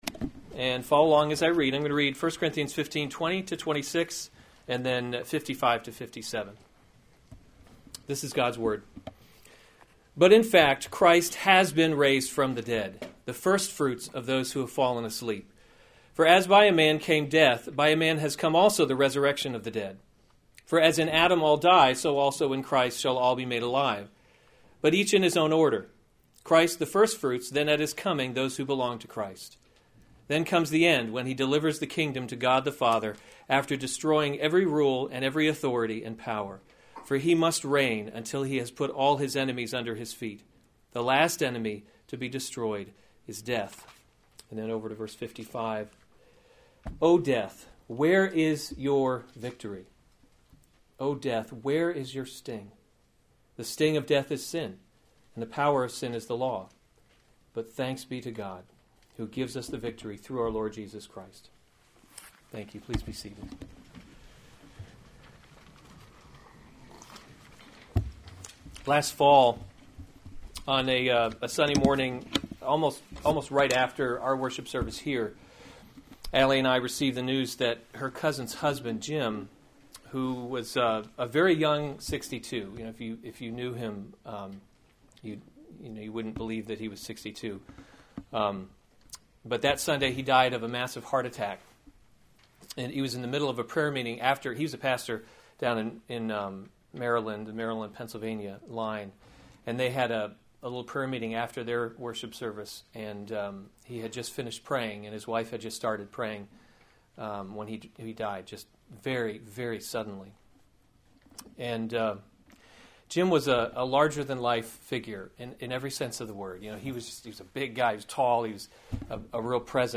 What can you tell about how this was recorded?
March 26, 2016 Special Services series Easter Service Save/Download this sermon 1 Corinthians 15 Other sermons from 1 Corinthians The Resurrection of Christ 15:1 Now I would remind you, brothers, [1] of […]